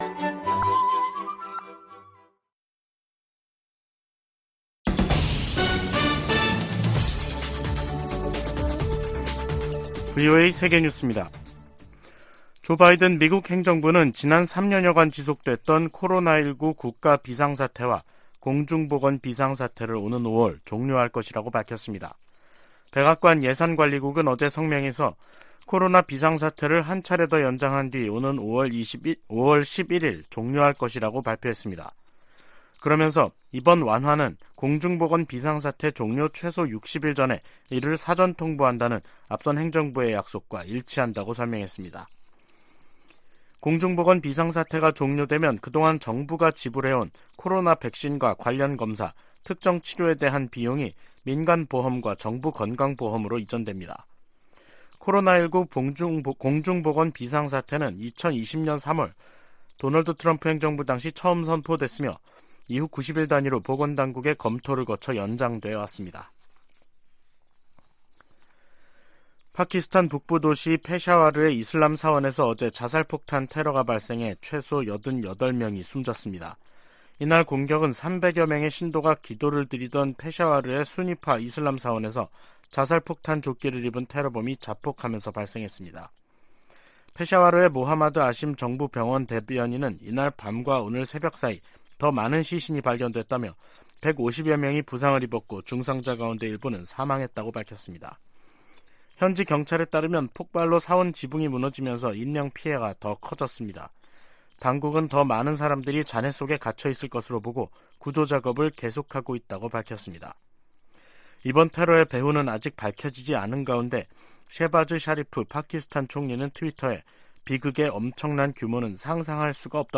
VOA 한국어 간판 뉴스 프로그램 '뉴스 투데이', 2023년 1월 31일 2부 방송입니다. 미-한 두 나라 국방 장관들이 서울에서 회담을 갖고, 한국에 대한 미국의 확고한 방위공약을 보장하기 위해 미국의 확장억제 실행력 강화 조치들을 공동으로 재확인해 나가기로 했습니다. 북한이 함경남도 마군포 엔진시험장에서 고체연료 엔진 시험을 한 정황이 포착됐습니다.